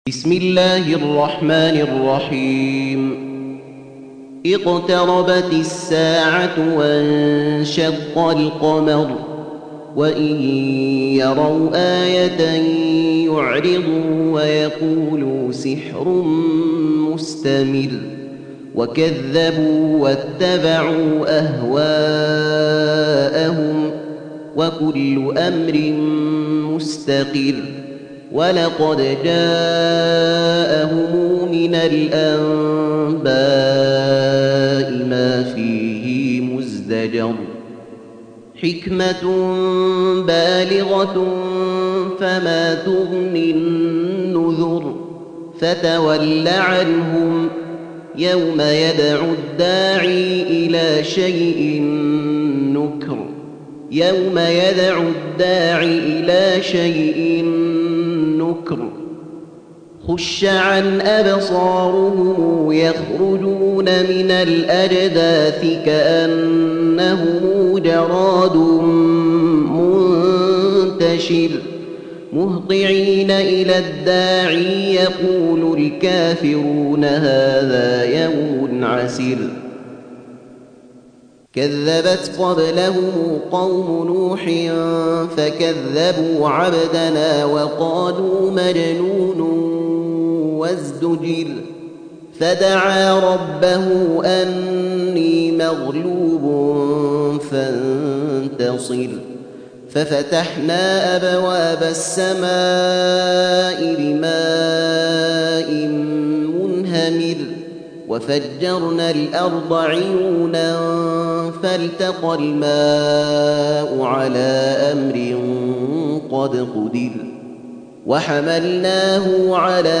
54. Surah Al-Qamar سورة القمر Audio Quran Tarteel Recitation
Surah Repeating تكرار السورة Download Surah حمّل السورة Reciting Murattalah Audio for 54. Surah Al-Qamar سورة القمر N.B *Surah Includes Al-Basmalah Reciters Sequents تتابع التلاوات Reciters Repeats تكرار التلاوات